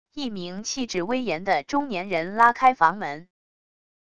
一名气质威严的中年人拉开房门wav音频